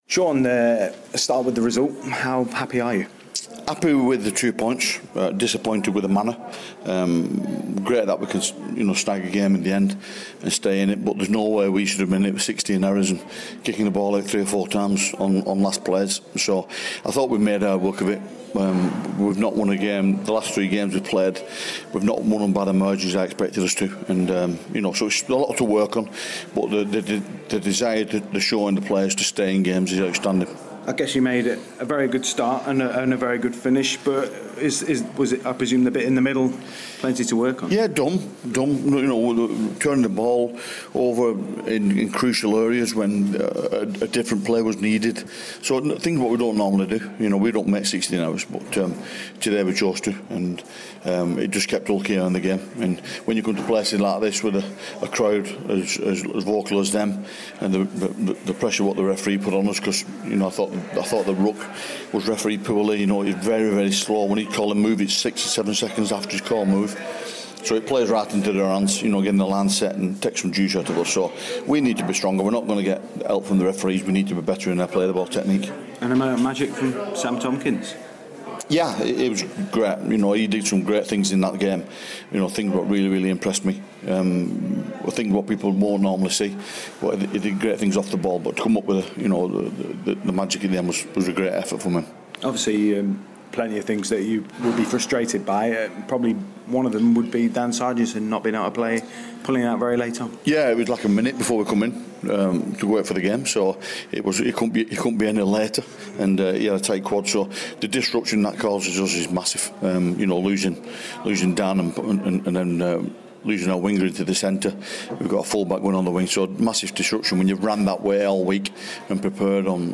Wigan Warriors Head Coach Shaun Wane speaks